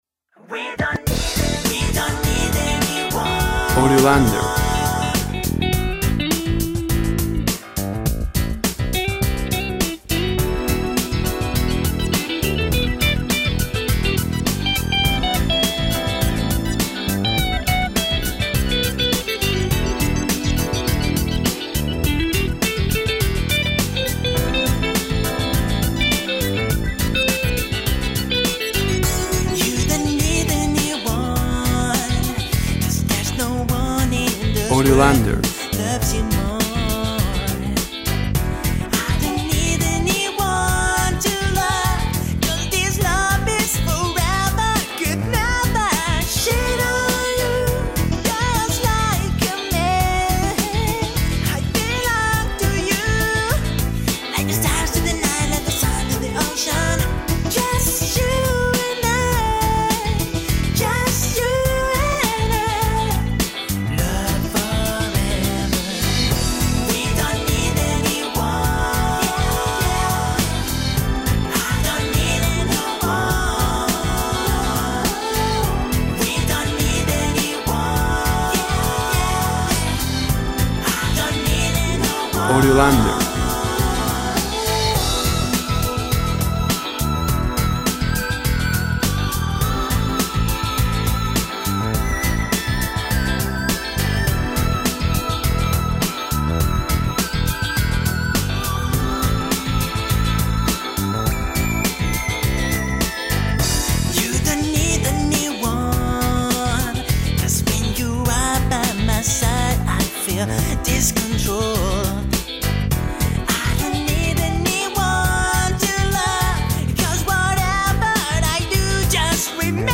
Funky vibe, relax, pop, Dance.
Tempo (BPM) 103